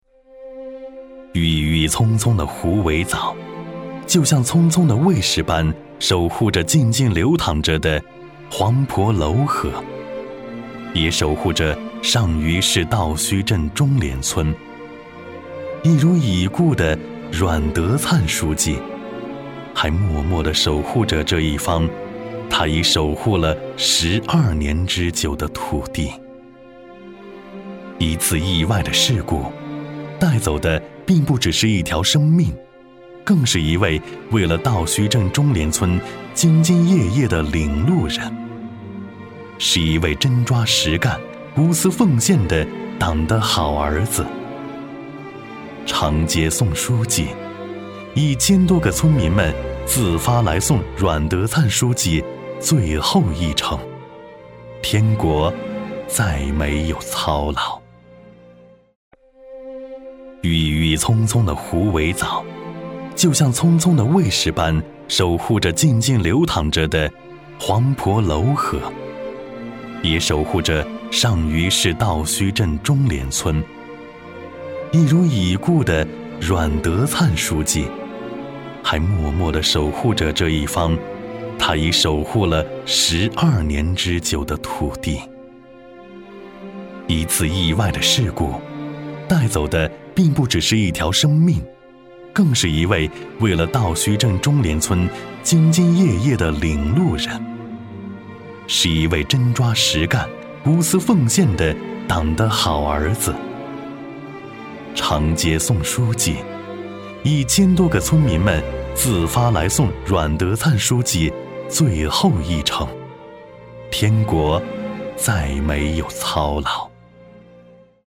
男S356 国语 男声 专题片-人物劳模-磁性、抒情 大气浑厚磁性|沉稳